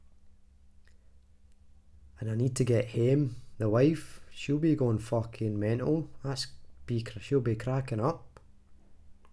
glaswegian
scottish